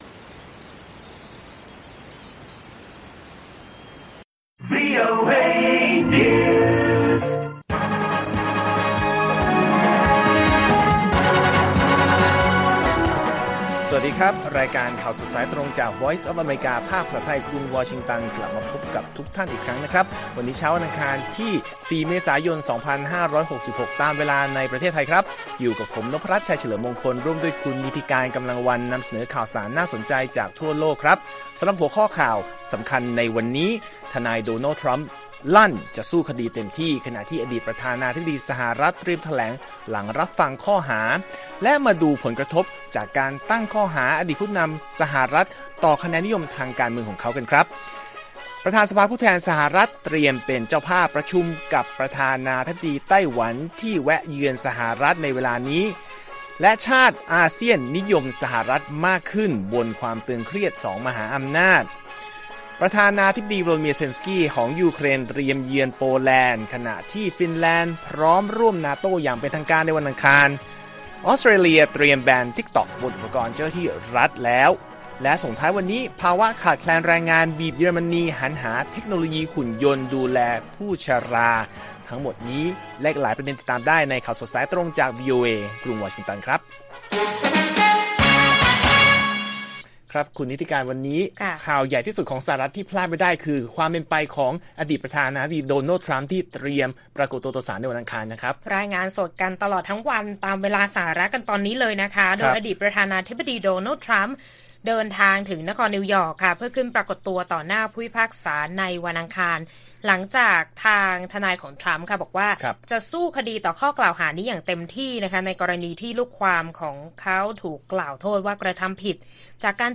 ข่าวสดสายตรงจากวีโอเอไทย 6:30 – 7:00 น. วันที่ 4 เม.ย. 2566